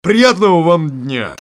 /server/sound/sound/quake/female/